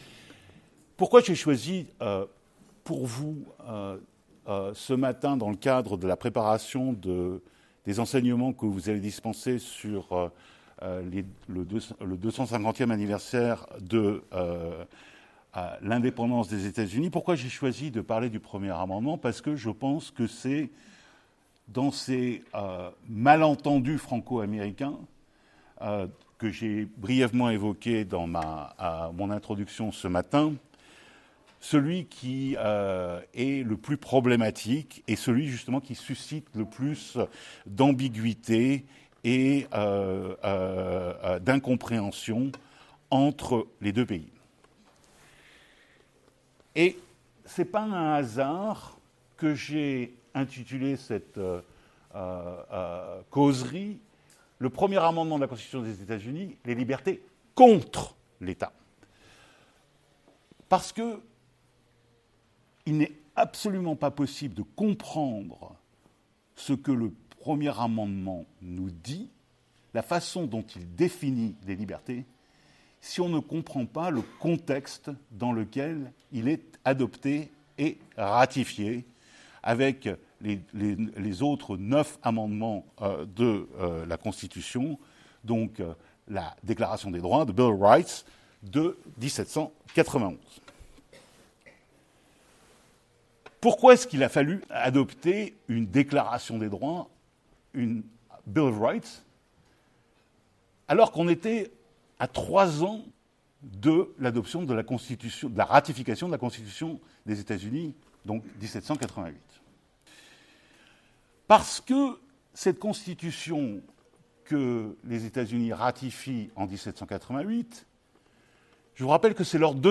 [Conférence]